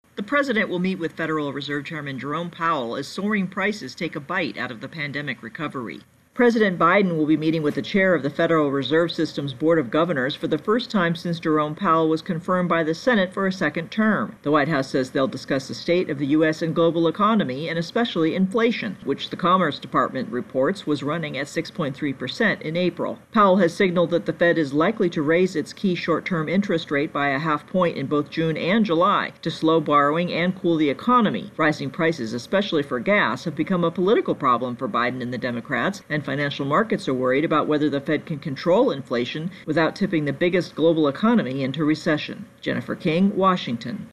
Biden Fed Chair intro voicer